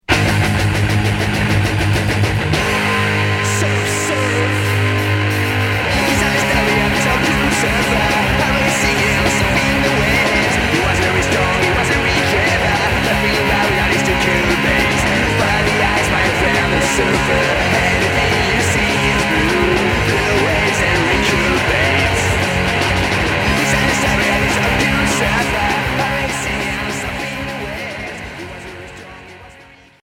Hardcore Deuxième 45t